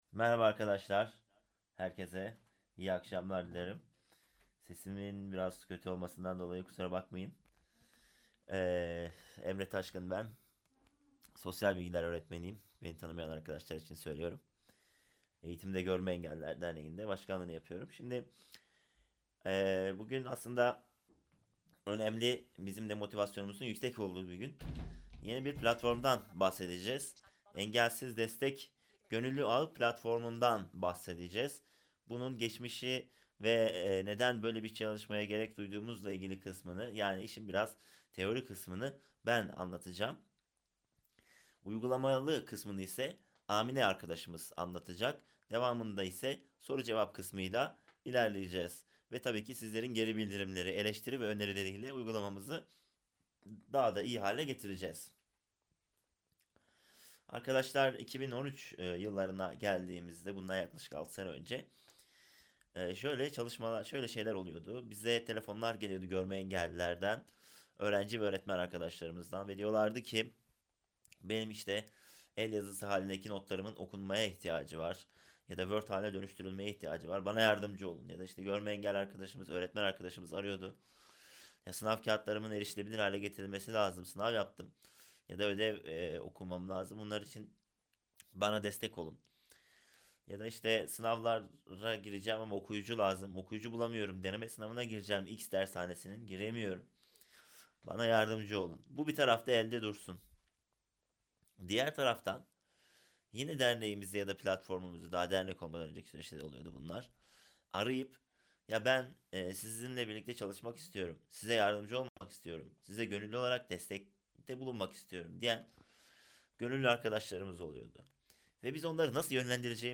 Engelsiz Destek Gönüllü Ağı Platformu tanıtım etkinliği, 31 Ekim 2019 Perşembe tarihinde, EGED - Uzaktan Eğitim Akademisi TeamTalk sunucularında yapıldı.
Etkinlik soru - cevap bölümünün ardından sona erdi.